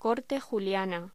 Locución: Corte juliana
voz